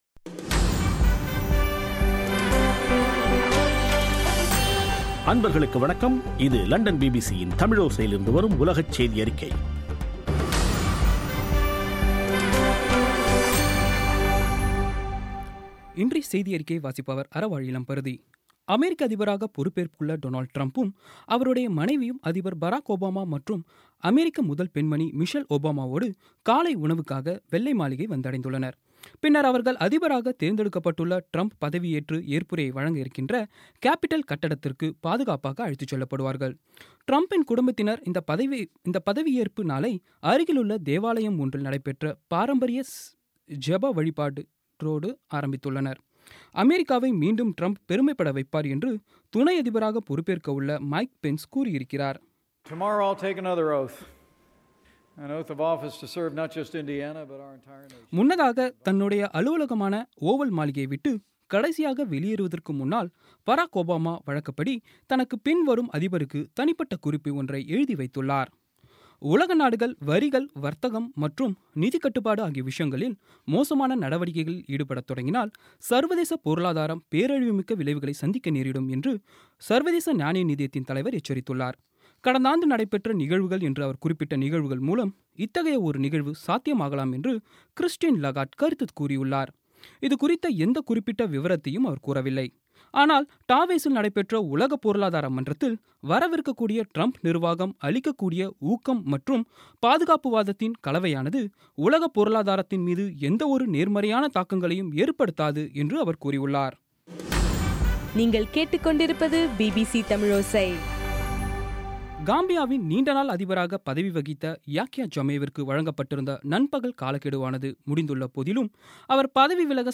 பிபிசி தமிழோசை செய்தியறிக்கை (20/01/2017)